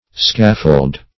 Scaffold \Scaf"fold\, v. t.